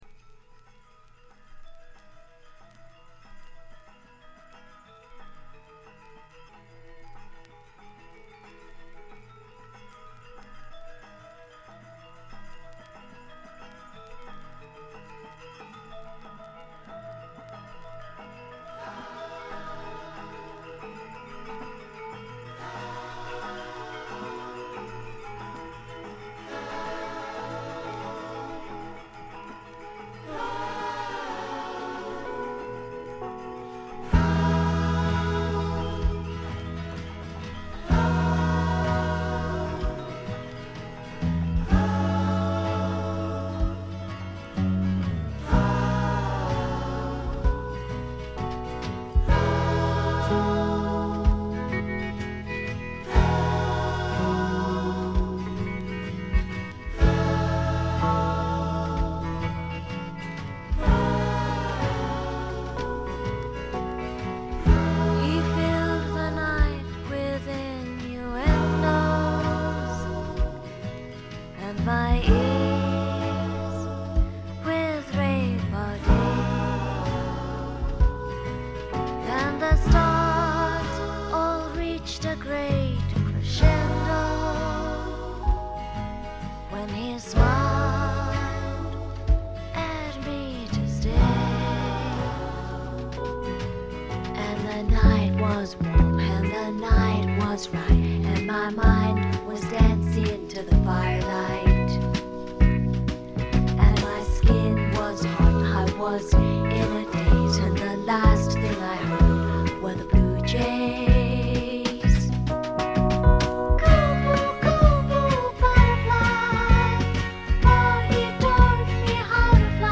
ごくわずかなノイズ感のみ。
試聴曲は現品からの取り込み音源です。
Lead Vocals